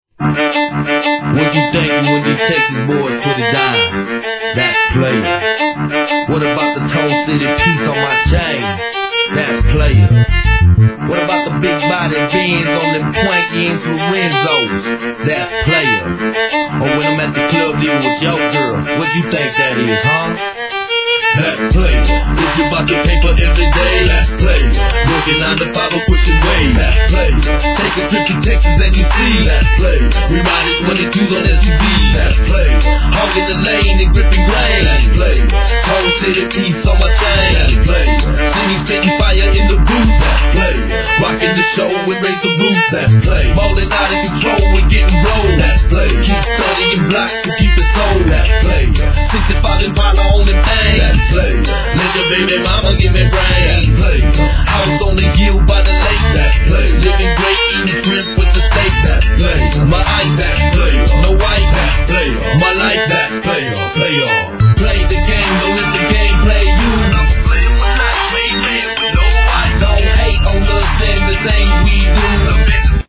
G-RAP/WEST COAST/SOUTH
強烈なヴァイオリンが印象大!!